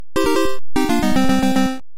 Игровой процесс в Танчики